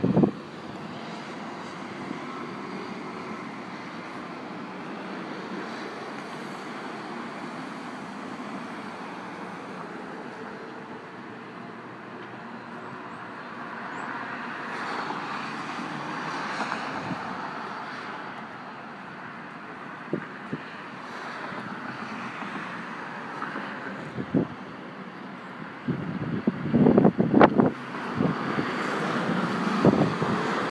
Field Recording
Location: Sidewalk of Hempstead Turnpike
Sounds heard: cars, cross walk beeping, wind
Turnpike.mp3